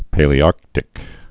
(pālē-ärktĭk, -ärtĭk)